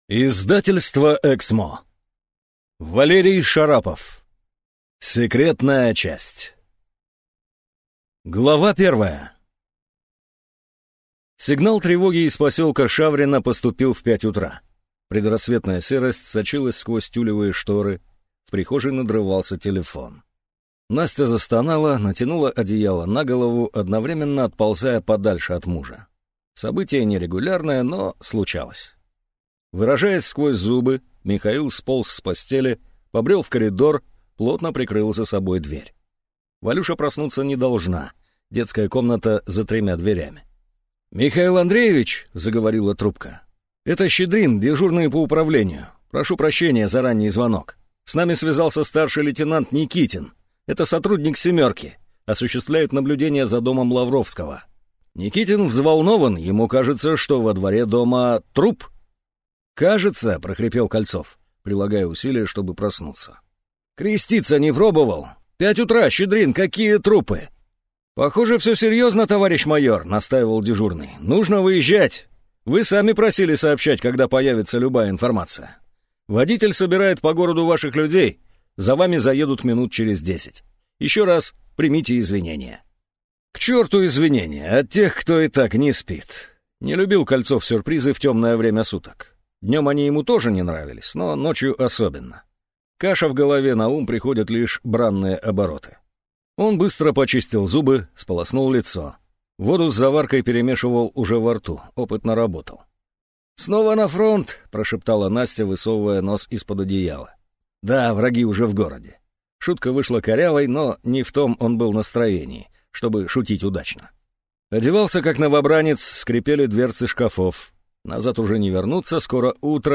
Аудиокнига Секретная часть | Библиотека аудиокниг